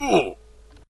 should be correct audio levels.
hurt.ogg